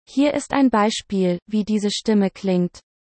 Index of /overte/content/marketplaceItems/textToSpeech/webApp/mp3s/samples/de-DE-Wavenet-A/